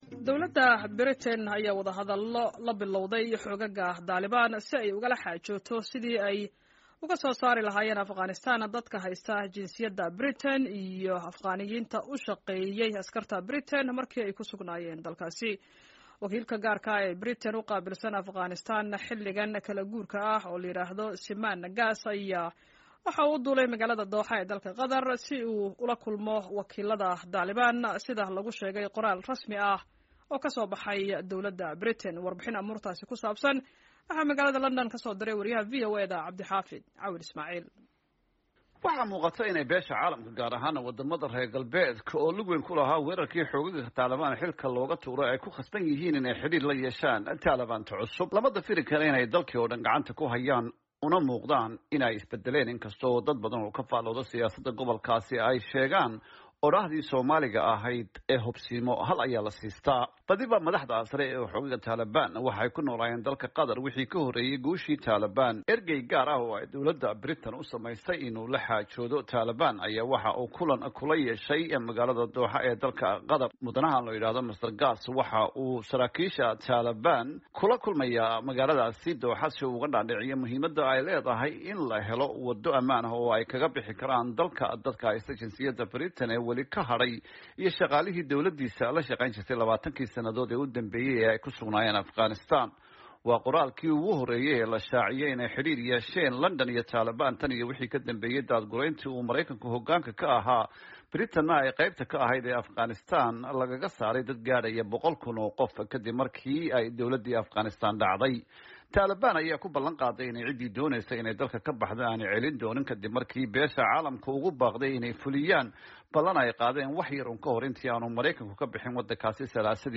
LONDON —